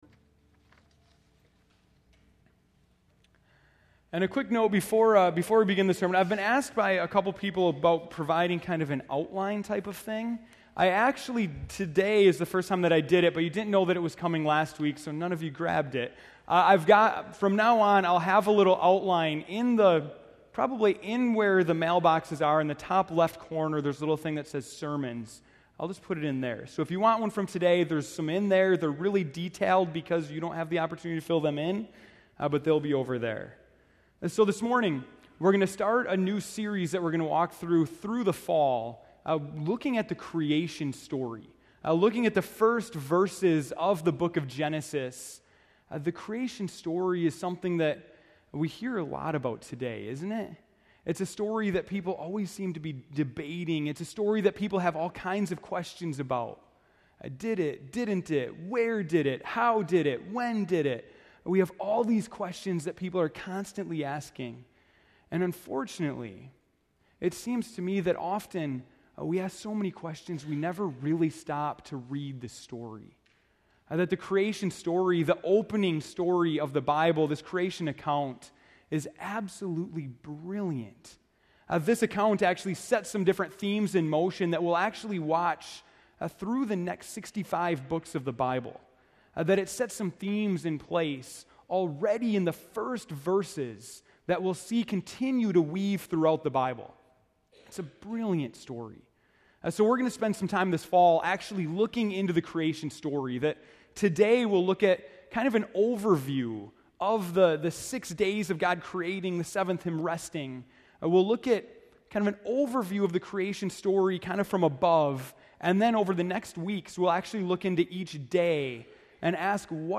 September 8, 2013 (Morning Worship)